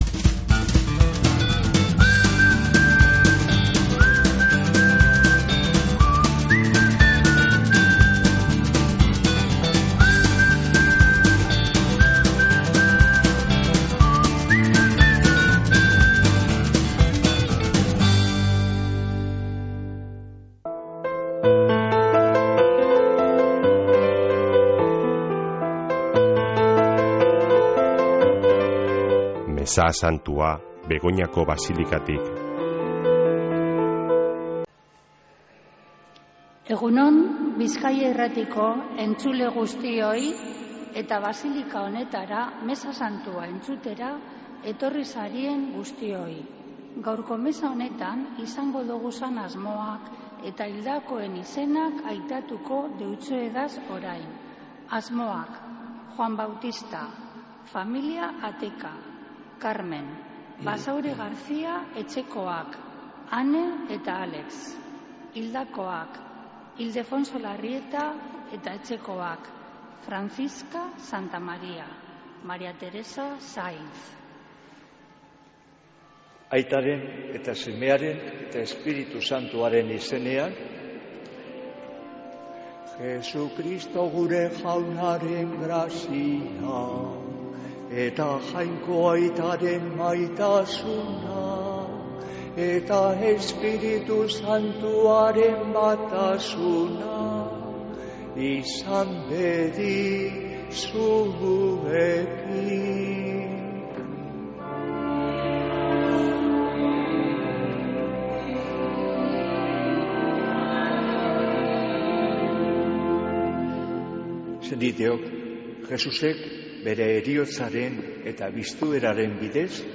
Mezea Begoñako Basilikatik | Bizkaia Irratia
Mezea (25-04-24)